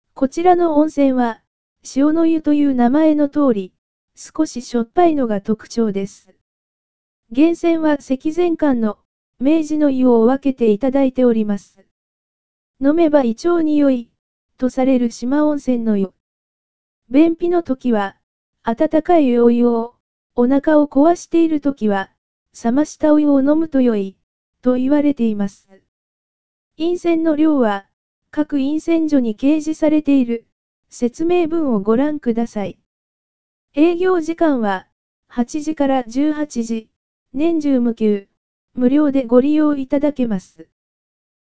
塩の湯飲泉所 – 四万温泉音声ガイド（四万温泉協会）